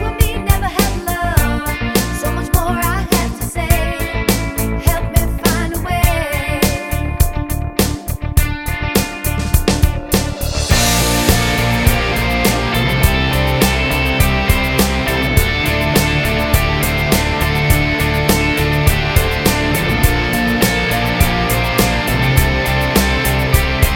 One Semitone Down Pop (2000s) 4:05 Buy £1.50